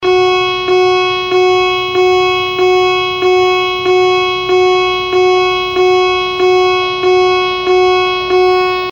alarm-porte_24805.mp3